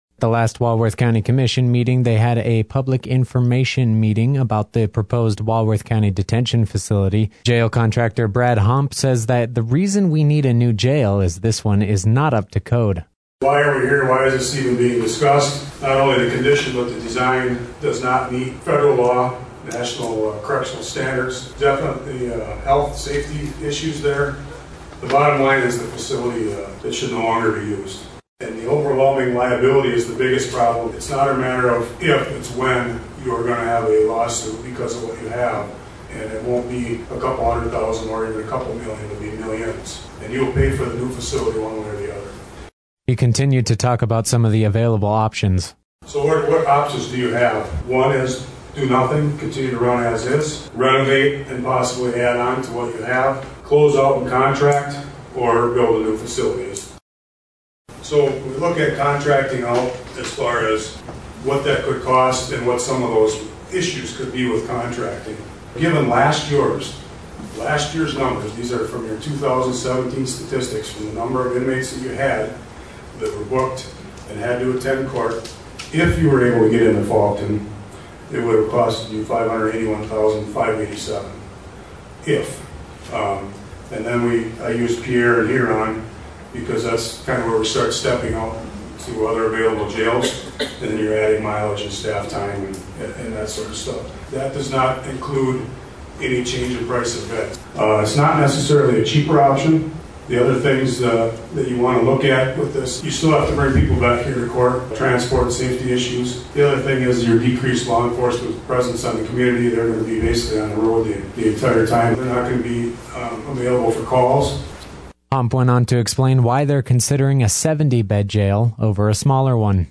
PUBLIC-JAIL-MEETING-w.mp3